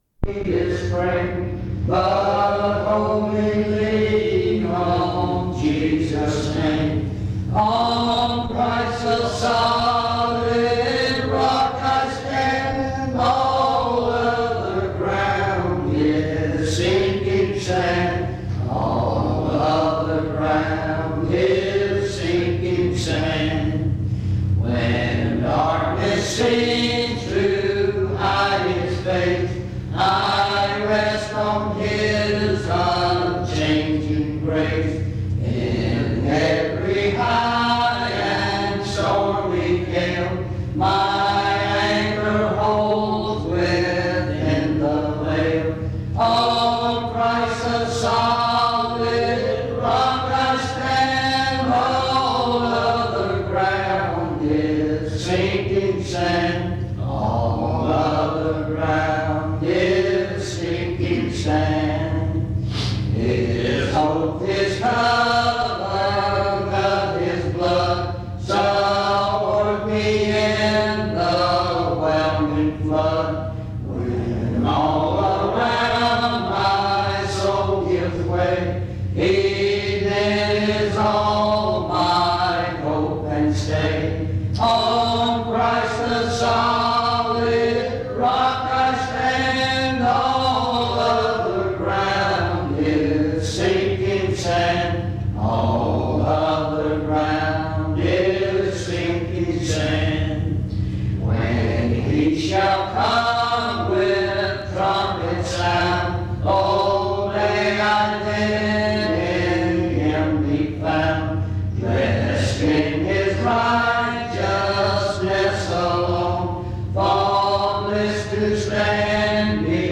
Reidsville/Lindsey Street Primitive Baptist Church audio recordings